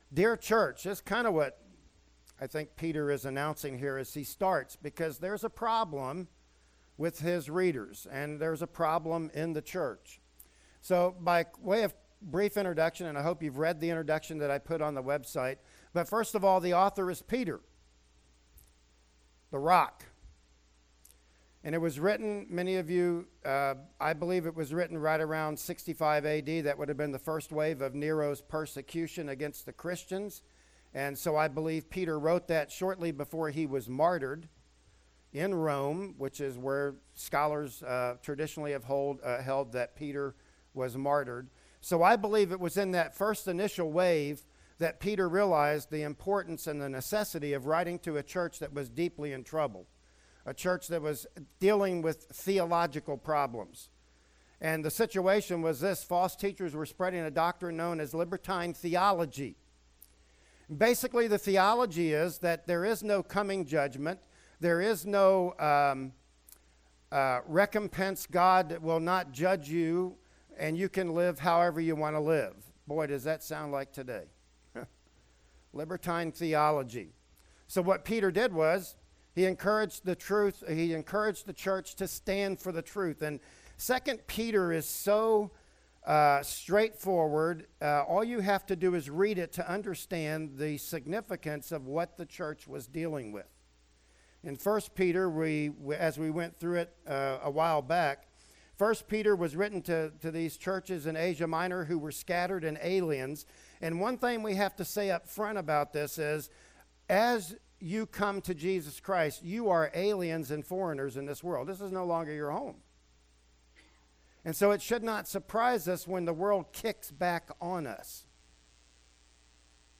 "2 Peter 1:1-2" Service Type: Sunday Morning Worship Service Bible Text